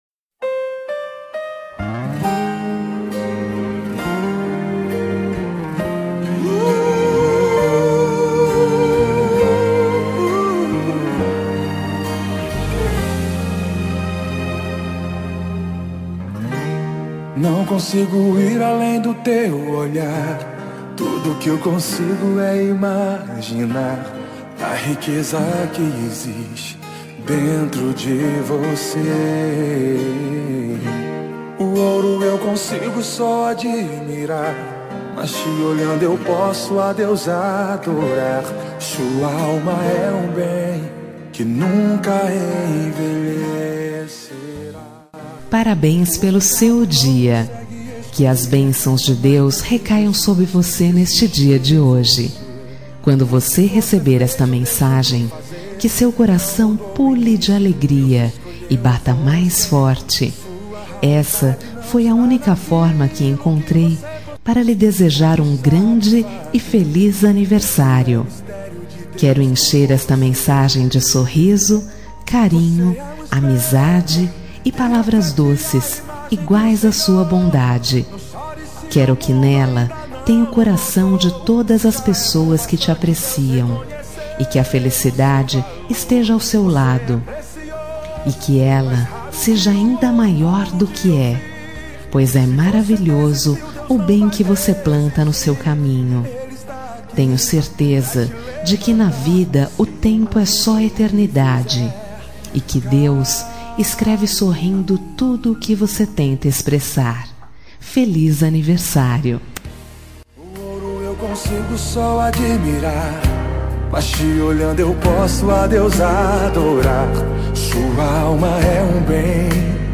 Aniversário Pessoa Especial Gospel – Voz Masculina – Cód: 6031